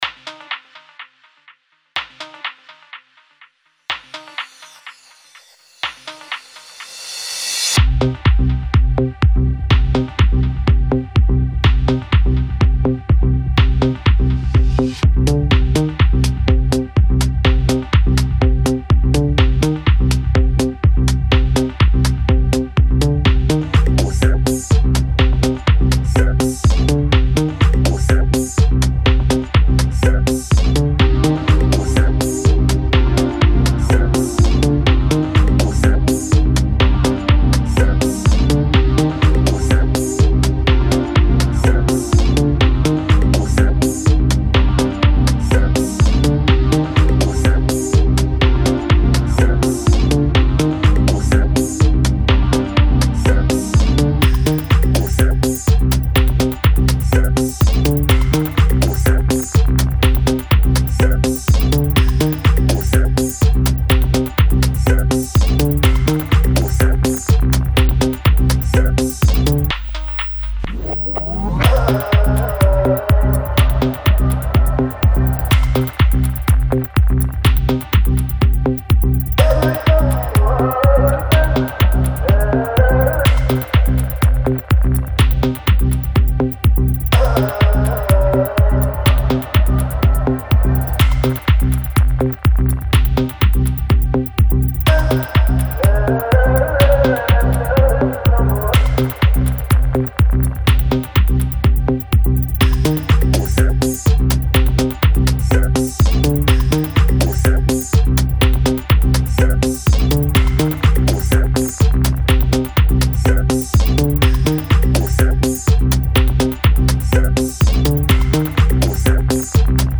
minimal techno
Genre Minimalism